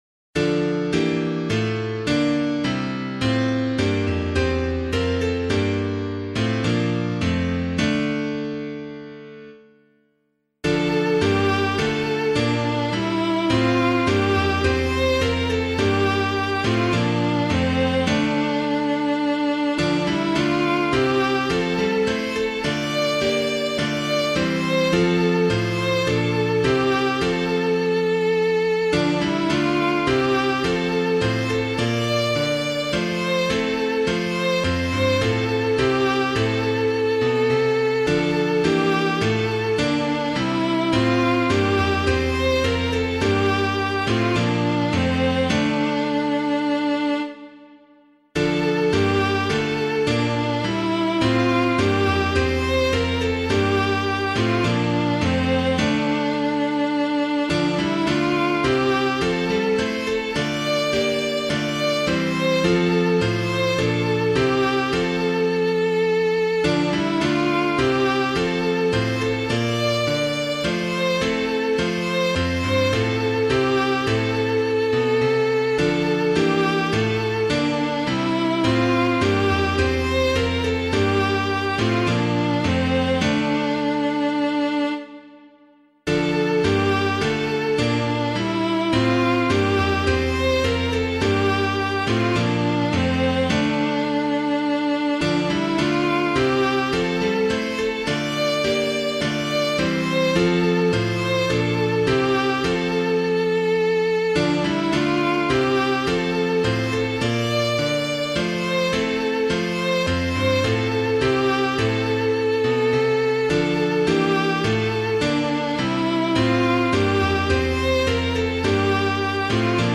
Composer:    English melody;
piano
O God of Earth and Altar [Chesterton - KING'S LYNN] - piano.mp3